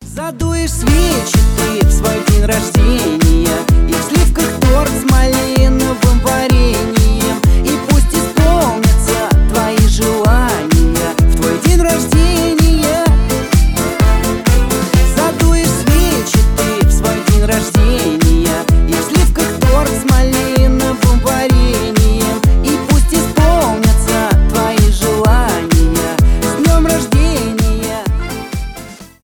веселые
позитивные
поп